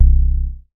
MoogAlone A.WAV